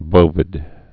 (bōvĭd)